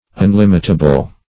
Unlimitable \Un*lim"it*a*ble\, a.